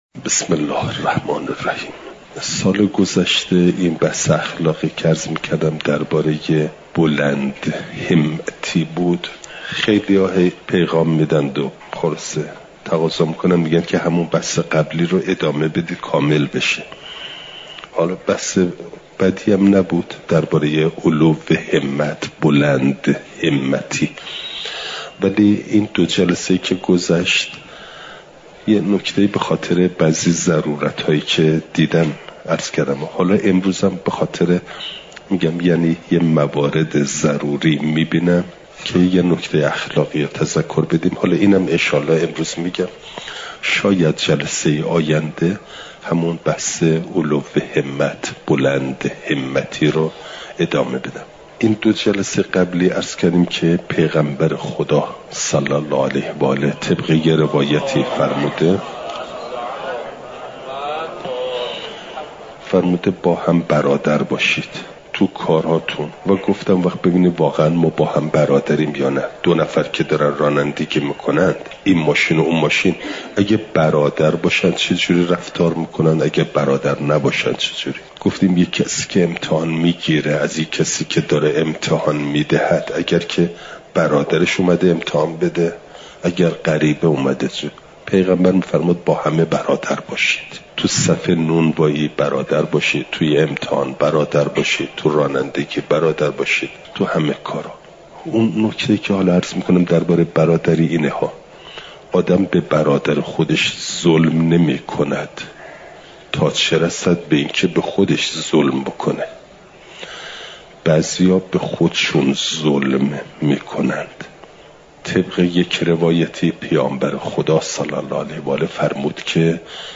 چهارشنبه ۲۳ مهرماه ۱۴۰۴، حرم مطهر حضرت معصومه سلام ﷲ علیها